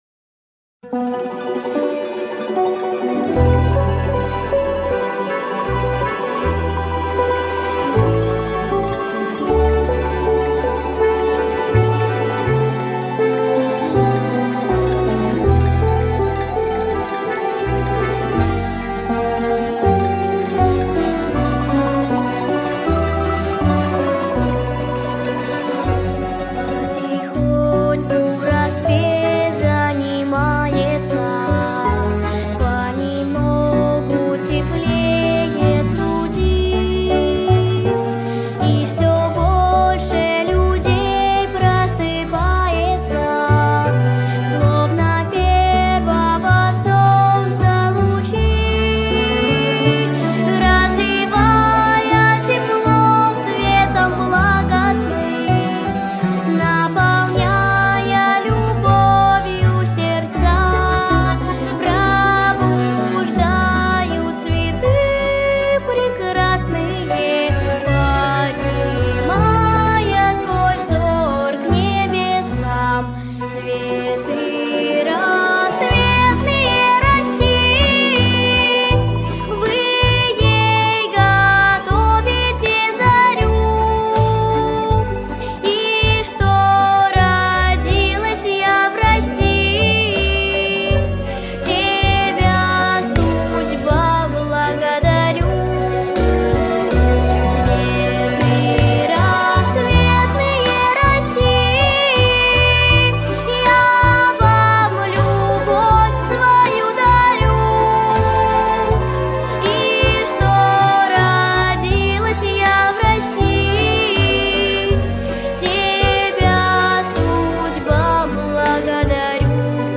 • Категория: Детские песни
патриотическая, народный мотив